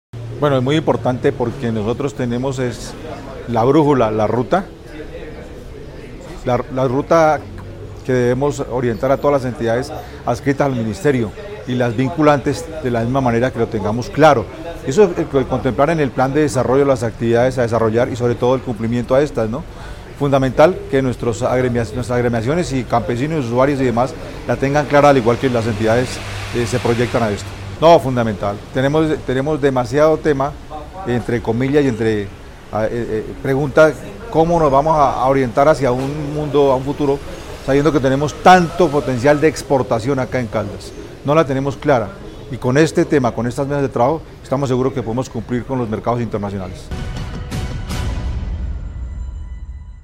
La Gobernación de Caldas, a través de su Secretaría de Agricultura y Desarrollo Rural de Caldas, llevó a cabo el tercer encuentro del año del Consejo Seccional de Desarrollo Agropecuario, Pesquero, Forestal, Comercial y de Desarrollo Rural (CONSEA) en el auditorio del Instituto Colombiano Agropecuario (ICA). En este espacio se socializó la ordenanza 1003, mediante la cual se adoptó el Plan Departamental de Extensión Agropecuaria (PDEA), una estrategia que busca fortalecer la ruralidad y abrir nuevas oportunidades de desarrollo para el campo caldense.